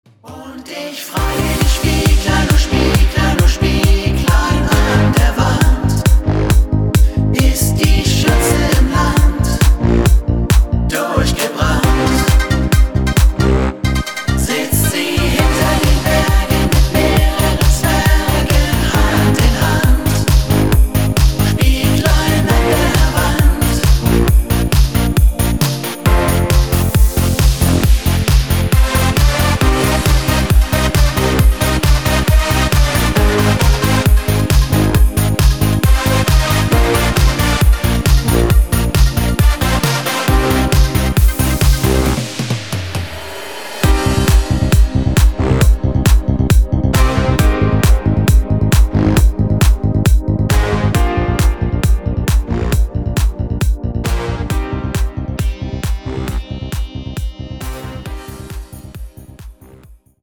neue moderne Version